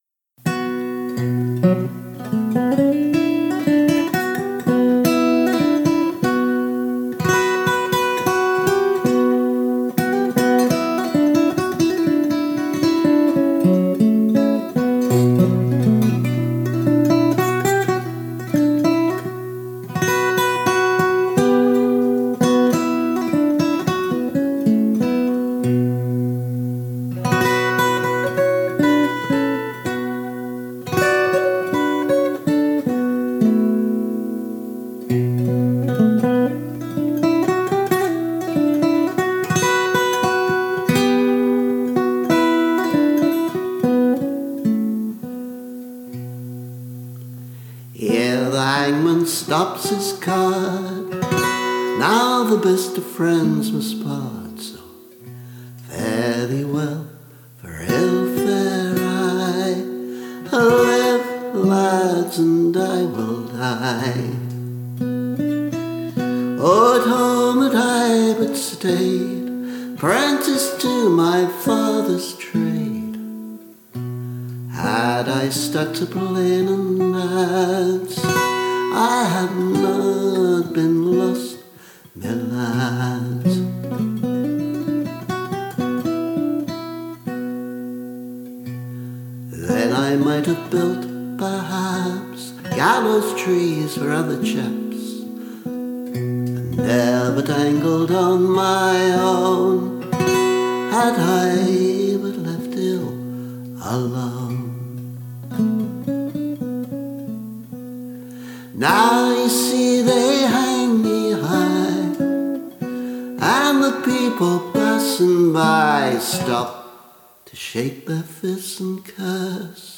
(Bouzouki and mountain dulcimer.)
This remix combines a more recent acoustic guitar and vocal version with part of the instrumental version appended.